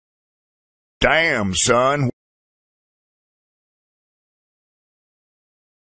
meme